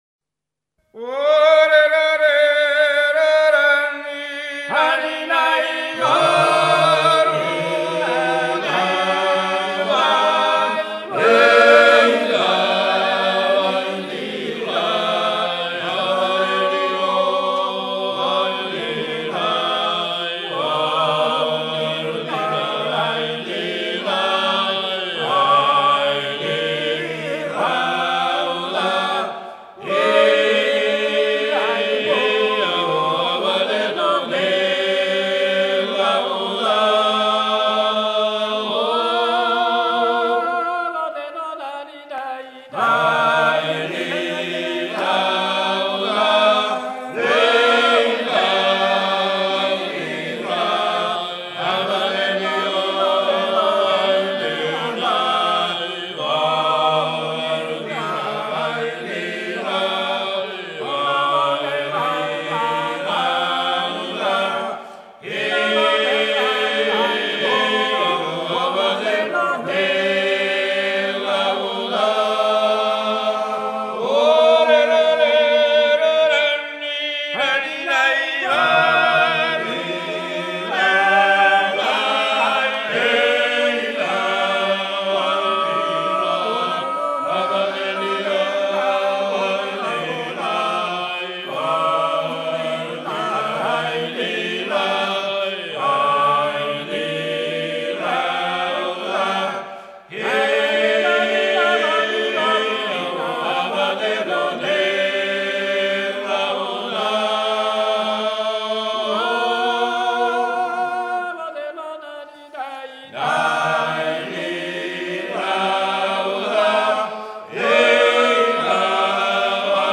Georgian Folklore